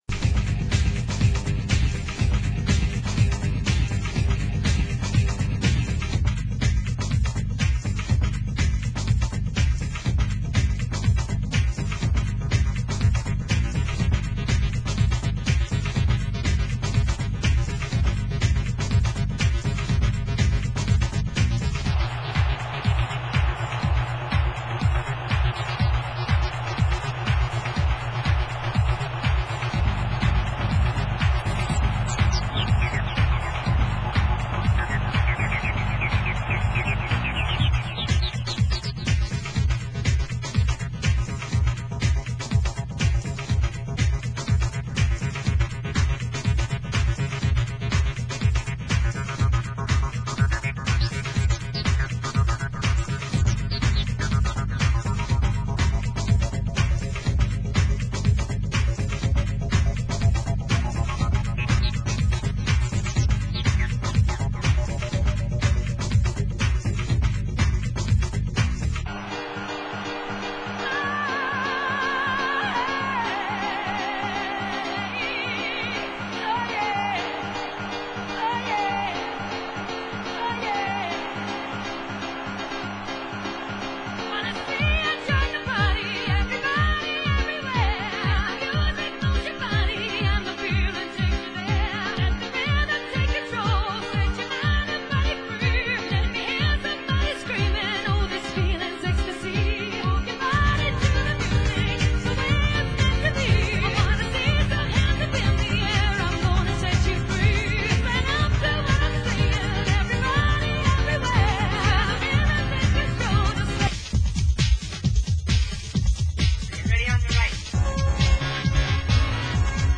Genre: Acid House